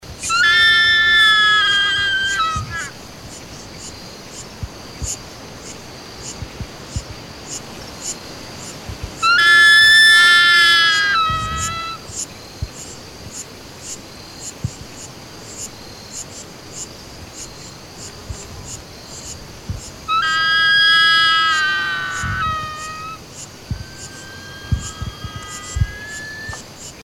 Aguila Coronada Taguató Común
Crowned Eagle Roadside Hawk